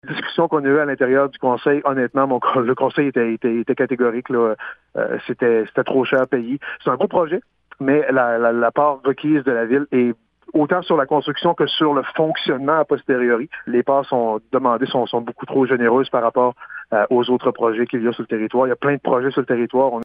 Daniel Côté ajoute que la décision du conseil est aussi pour être équitable avec les autres organismes du territoire qui ont besoin d’aide pour leu projets :